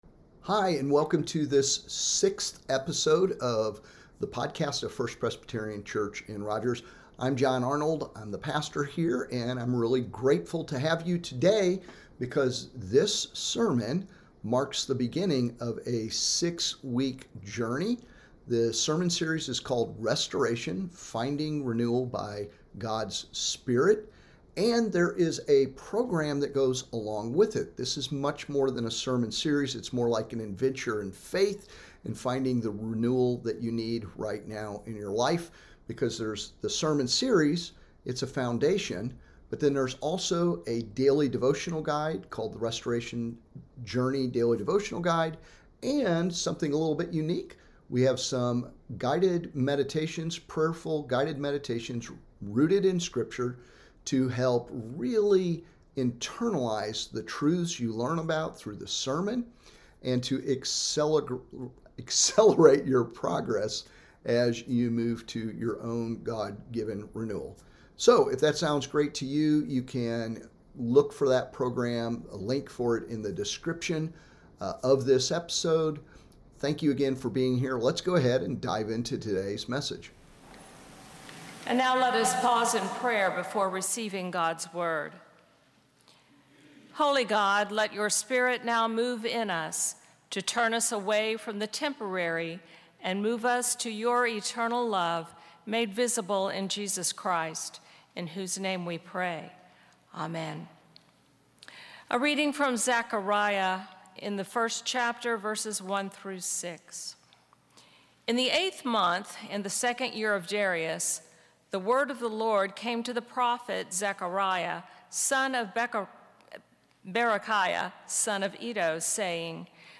Sermon-The-Restoration-Journey-Week-1-Return-to-God-and-God-will-Return-to-You.m4a